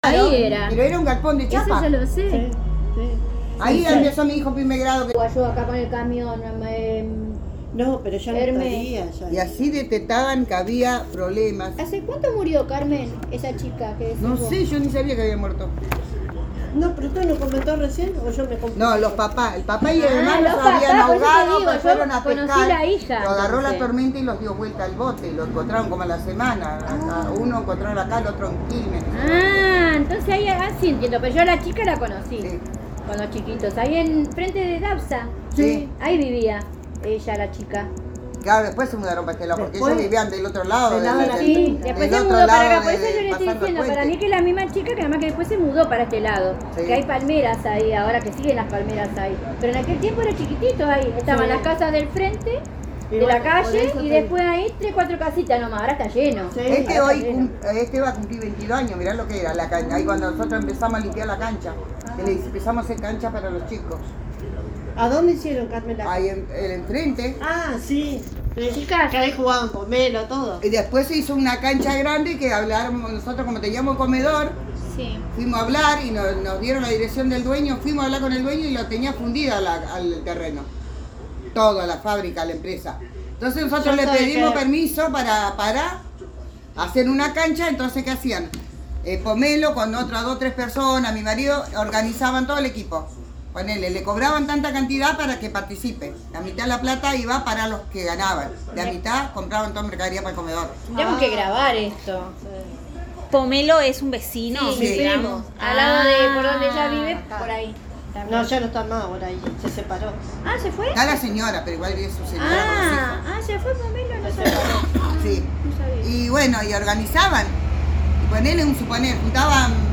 1 grabación sonora en soporte magnético